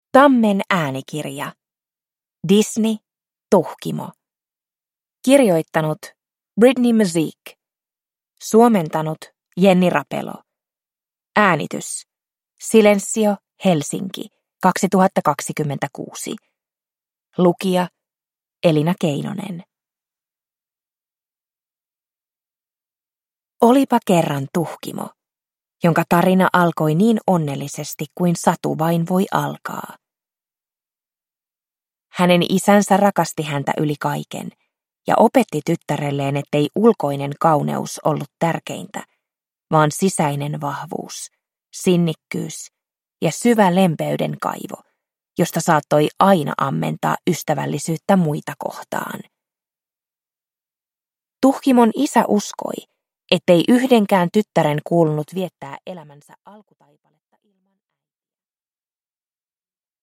Disney. Tuhkimo – Ljudbok